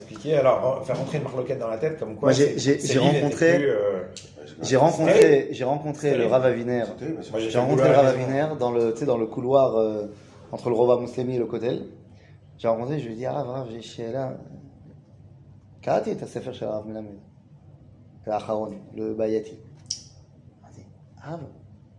שיעור מ 24 יוני 2022 53MIN הורדה בקובץ אודיו MP3